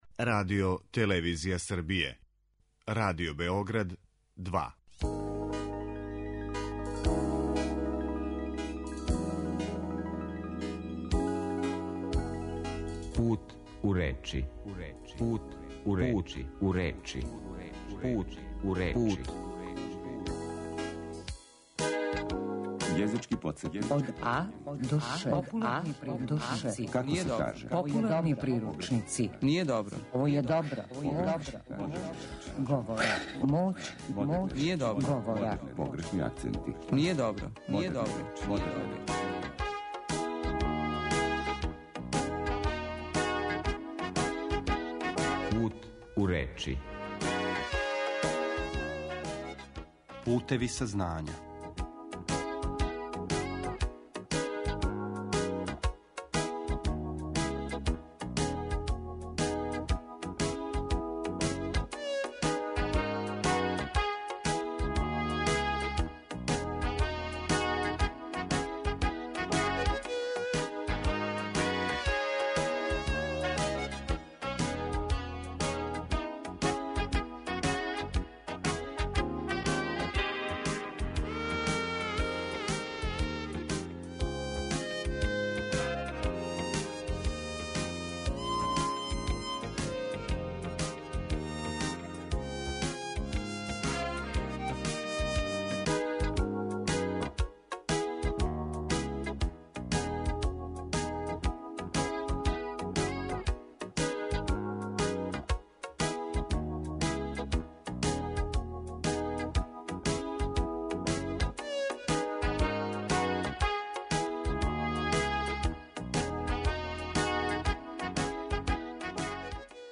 Емисија о лингвистици, нашем књижевном језику у теорији и пракси, свакодневној вербалној комуникацији и говору на медијима.